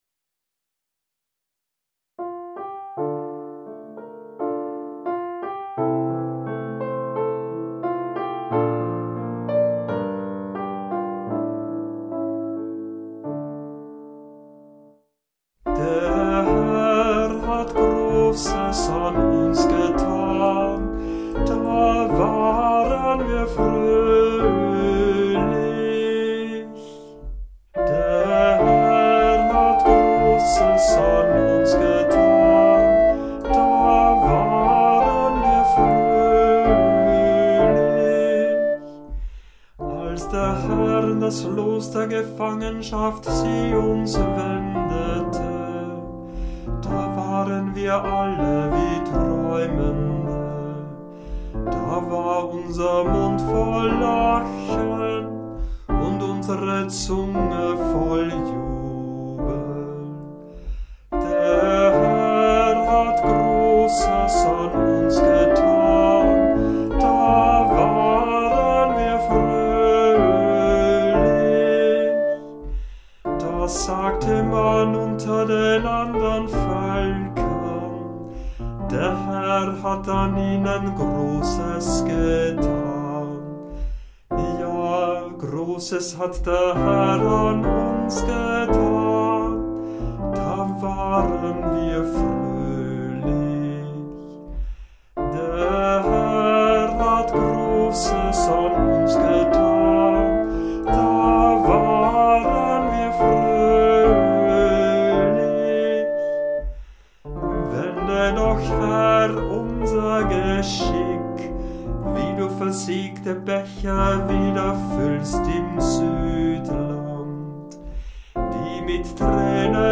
Hörbeispiele aus verschiedenen Kantorenbüchern